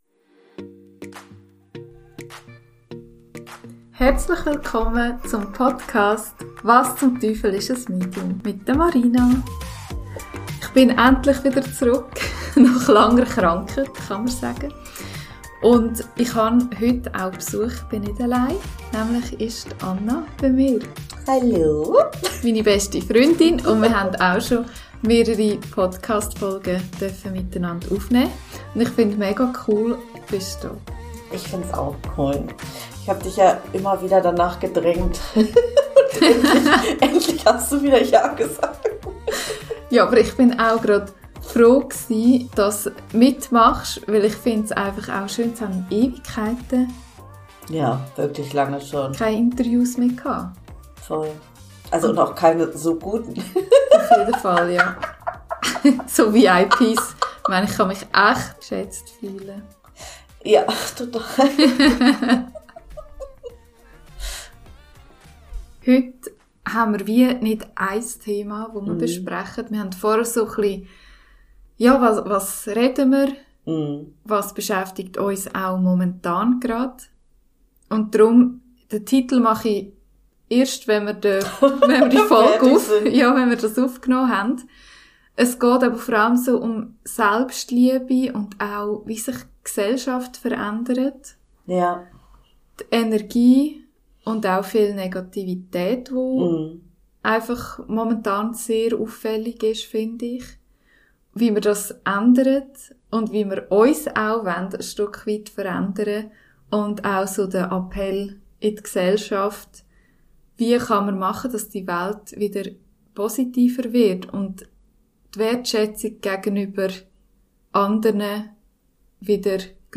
Ein ehrliches Gespräch, tief gehend leicht und herzlich zugleich mit Humor.